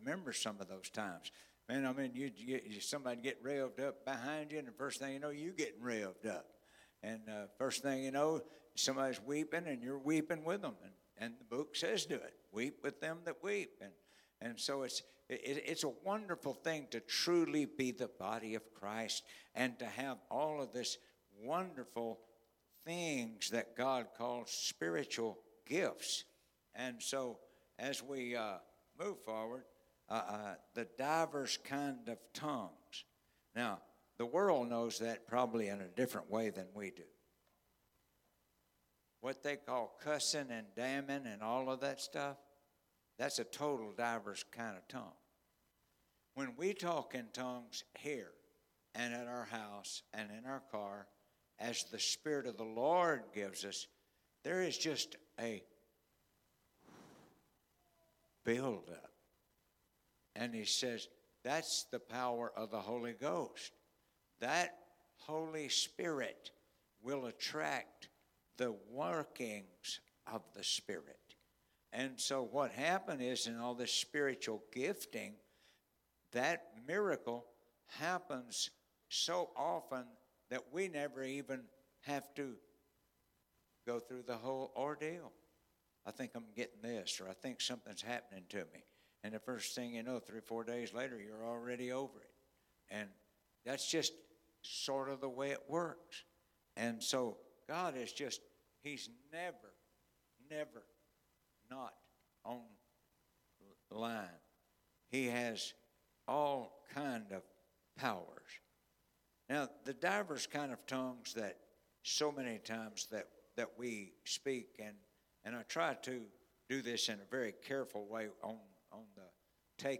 Sermons by United Pentecostal Church